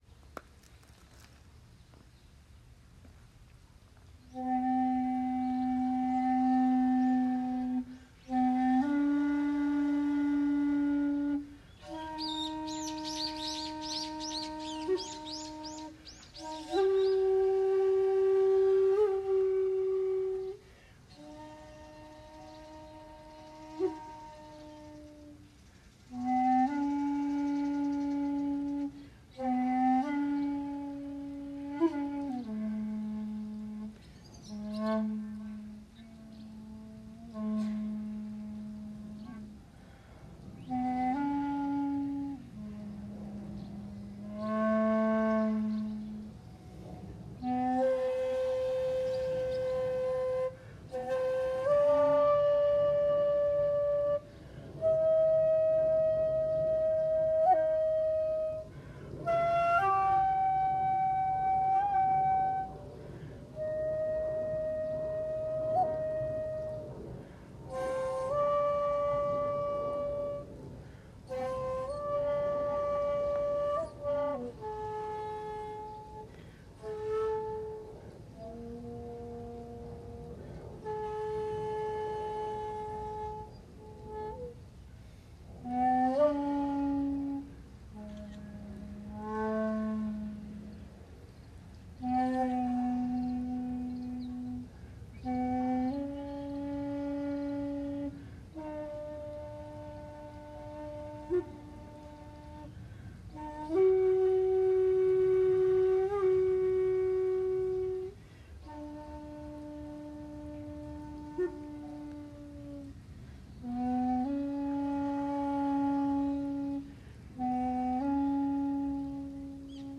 本堂と大師堂にお参りをし、今日最後の寺なので尺八を吹奏しました。
◆◆（尺八音源：安楽寺「水鏡」）